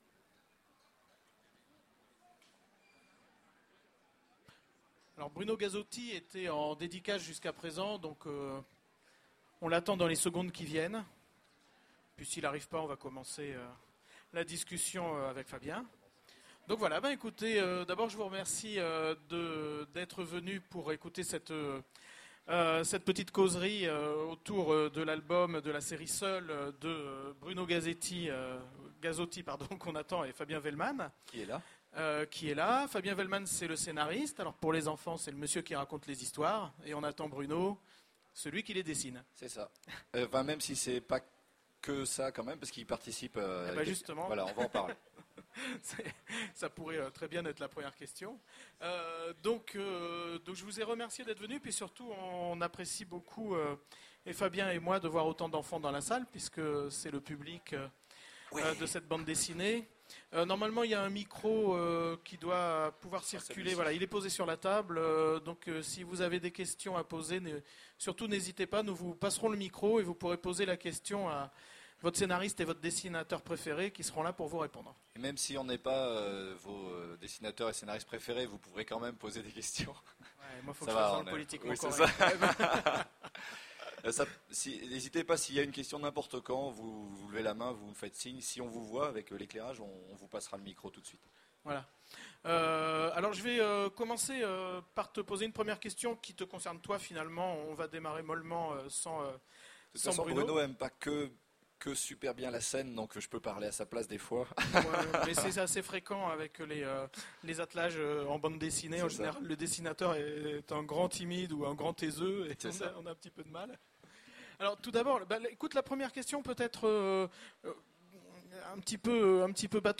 Voici l'enregistrement de la conférence/rencontre avec Fabien Vehlmann et Bruno Gazzotti aux Utopiales 2010 autour de Seuls.